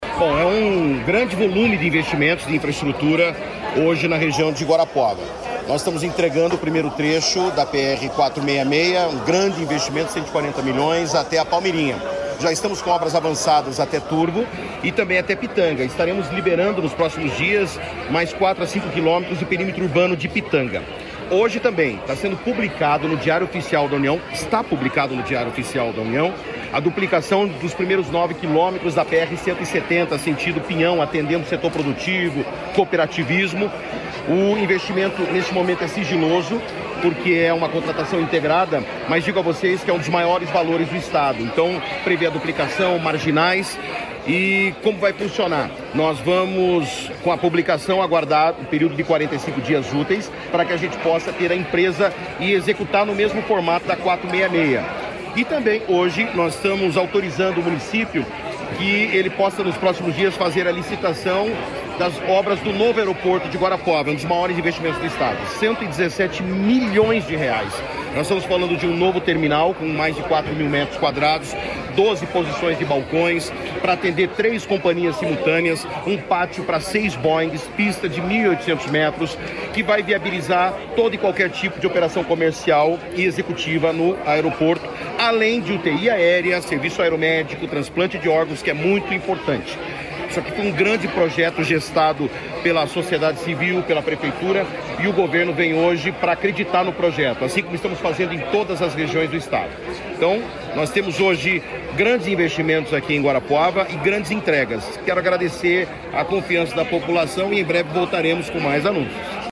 Sonora do secretário de Infraestrutura e Logística, Sandro Alex, sobre a ampliação do aeroporto de Guarapuava e o edital de duplicação e restauração em concreto da PR-170, no município